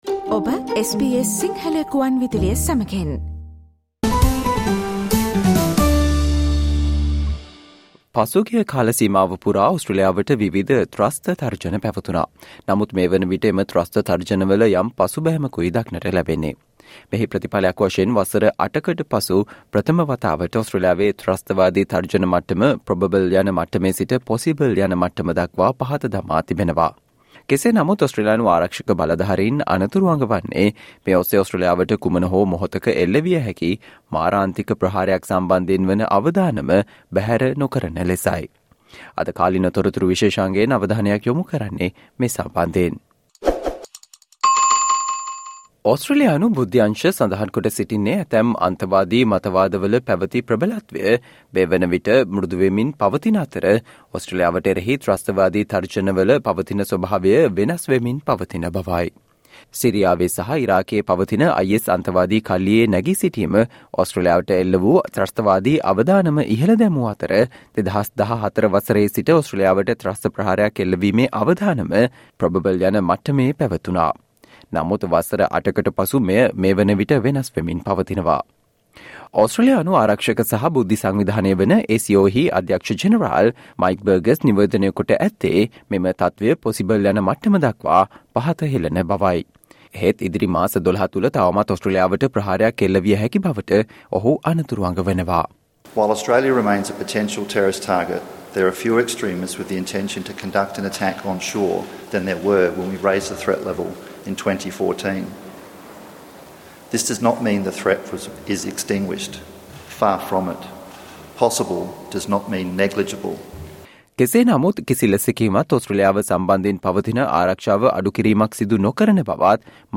Today - 29 November, SBS Sinhala Radio current Affair Feature on 'Possible does not mean negligible' - ASIO lowers terrorism threat level